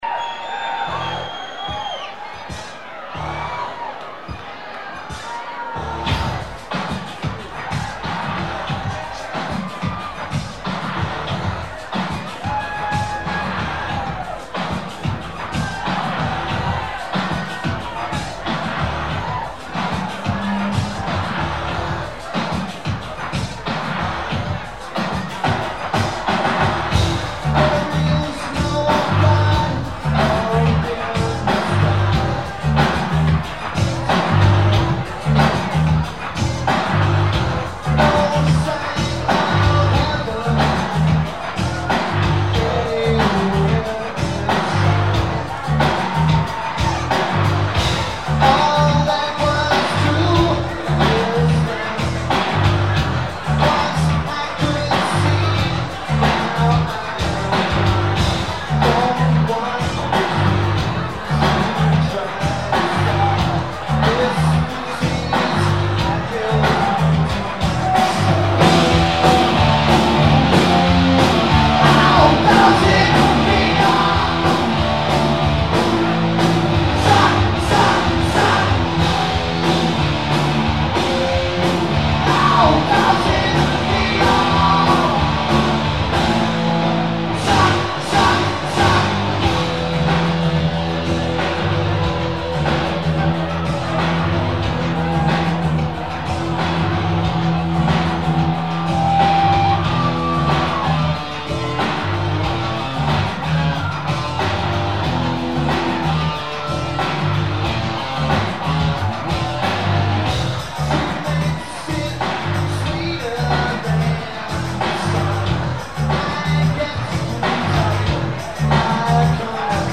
Tipitina's
Drums
Guitar
Vocals/Guitar/Keyboards